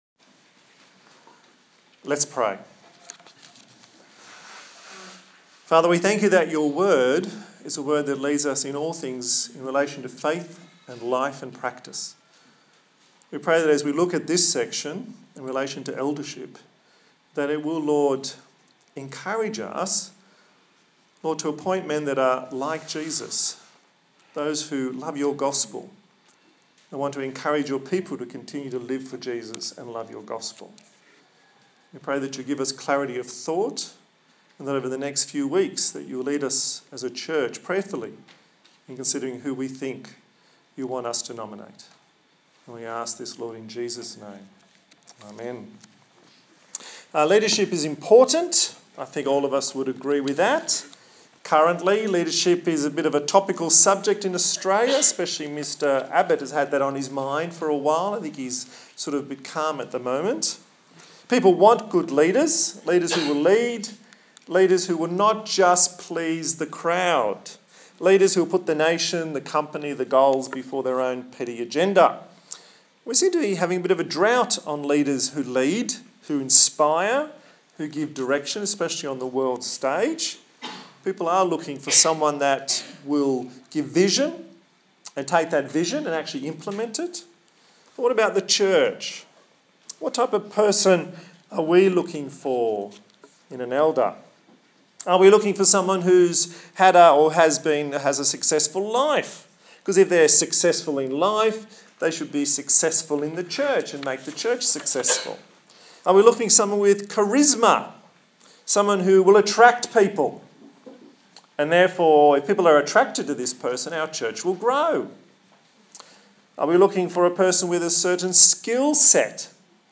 Titus 1:1-9 Service Type: Sunday Morning What does it take to be a good leader and what characteristics must they possess?